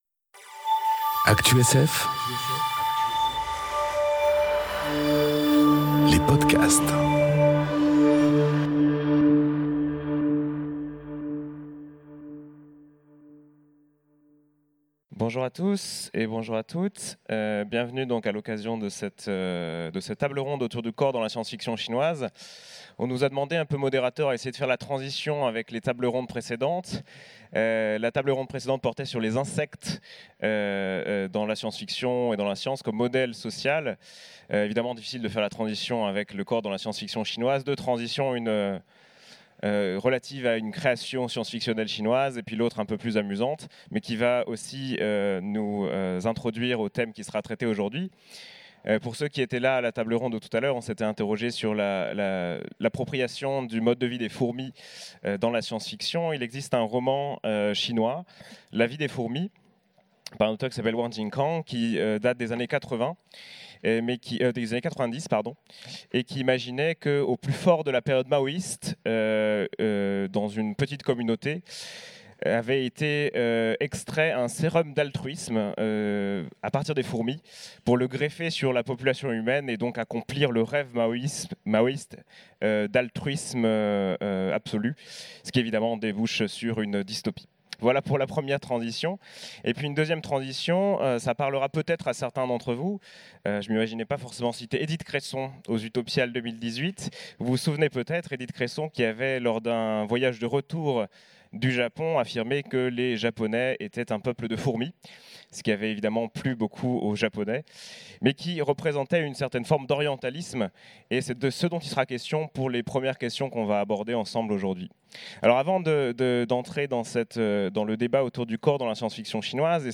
Conférence Le corps dans la science-fiction chinoise enregistrée aux Utopiales 2018